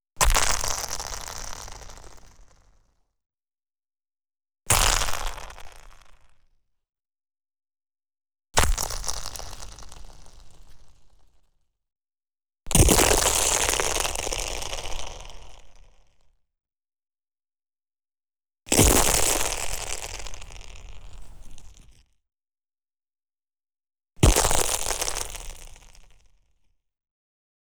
• snow samples - stepping and crushing 2.wav
Collection of recordings on January 10th 2011 during the Georgia winter storm. Various sleds, ice breaking, ice creaking, icy tension cracks, and heavy snow crunches/impacts.
snow_samples_-_stepping_and_crushing_2_DwO.wav